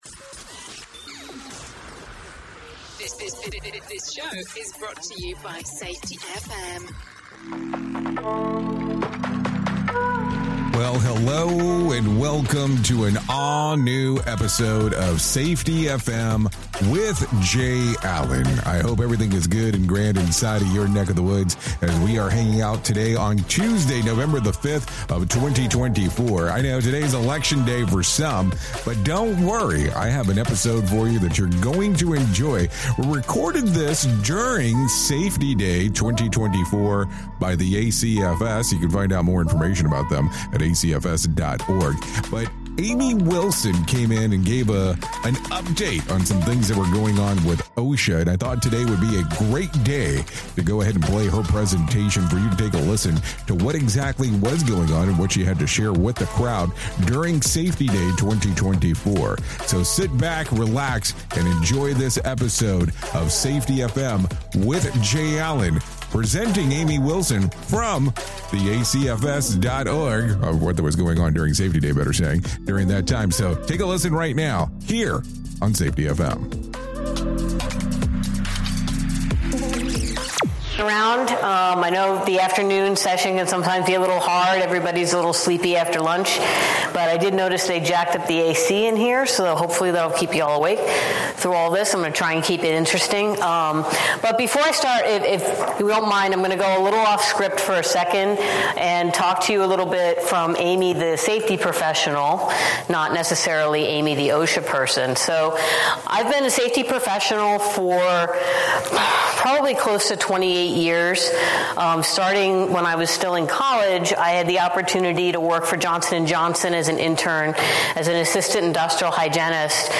This is a recording of her presentation at Safety Day 2024, presented by the ACFS.